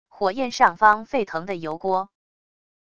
火焰上方沸腾的油锅wav音频